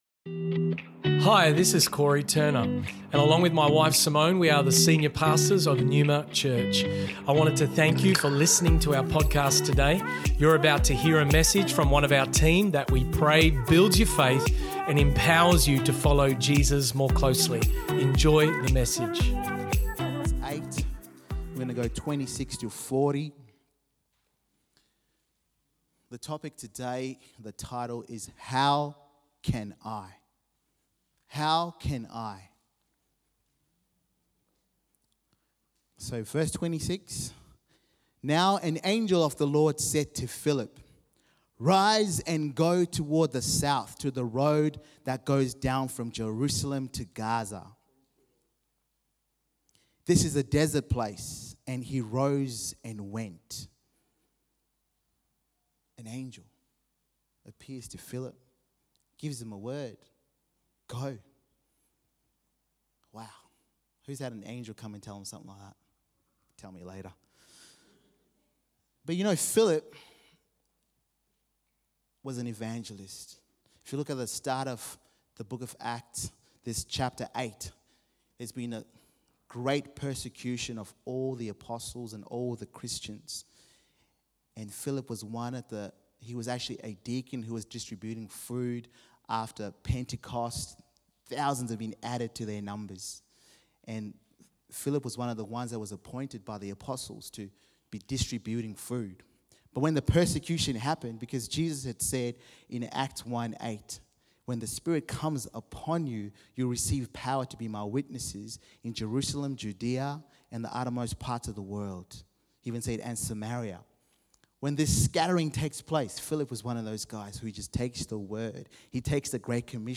Neuma Church Melbourne South Originally Recorded at the 10am Service on Sunday 26th March 2023.&nbsp